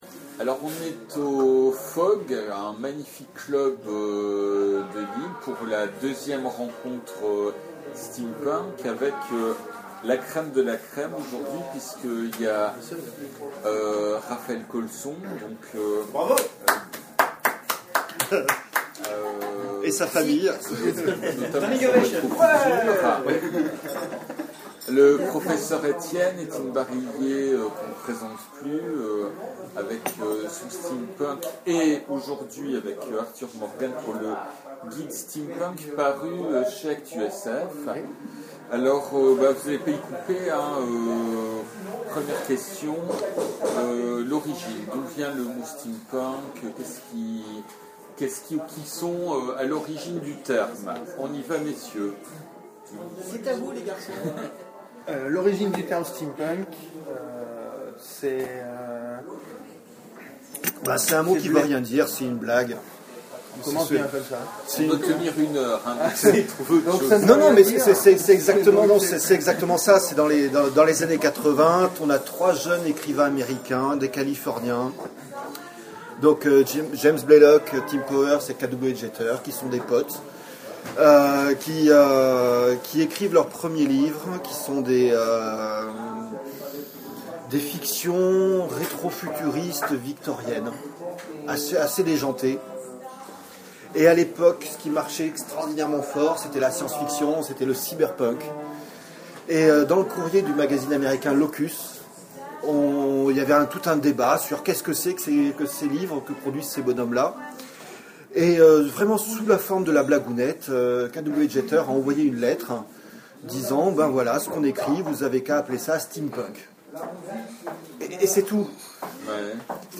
Conférence : Le steampunk